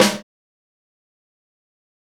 DrSnare37.wav